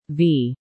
V_female.mp3